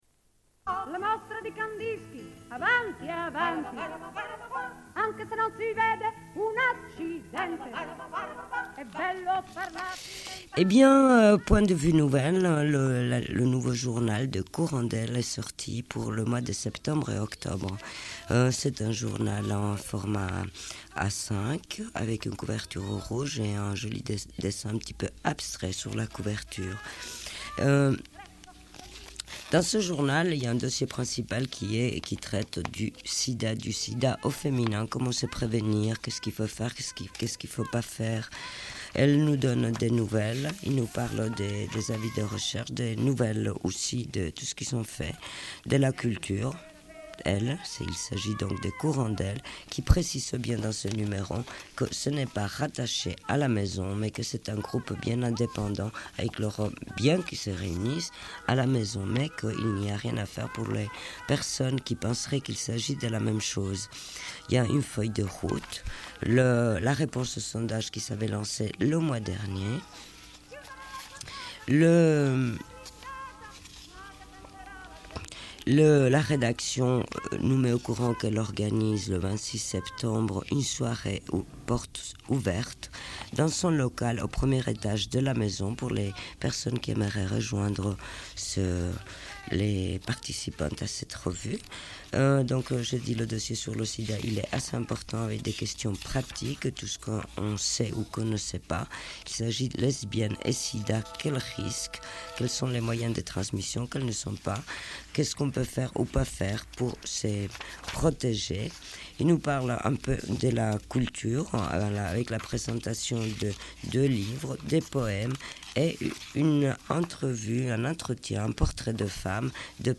Une cassette audio, face A31:32
Bulletin d'information de Radio Pleine Lune